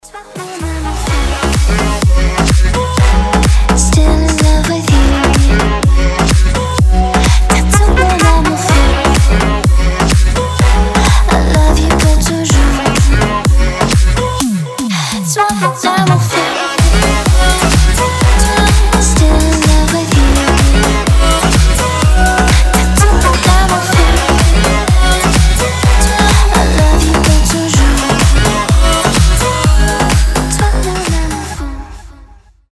• Качество: 320, Stereo
громкие
зажигательные
женский голос
Club House
ремиксы